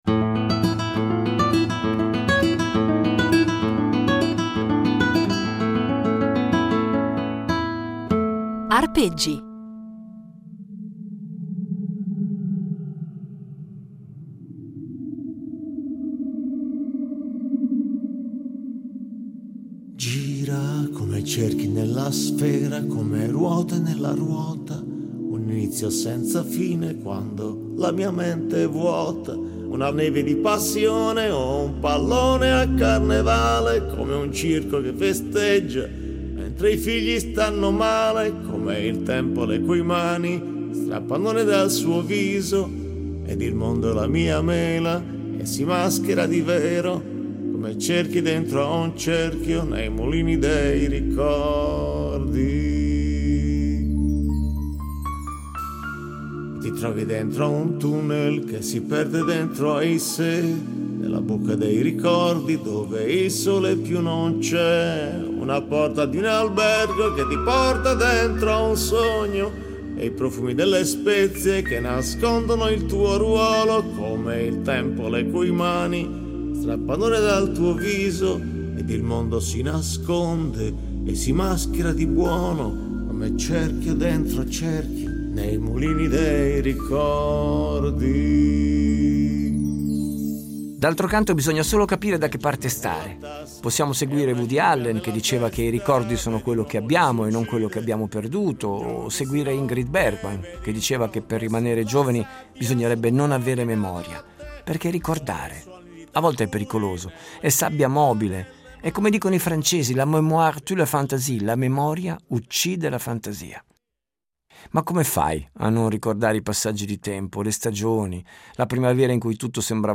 sax
chitarra
registrata nella primavera di quest’anno
supportato da versioni inedite, evocative e puramente strumentali di canzoni degli artisti che ha amato di più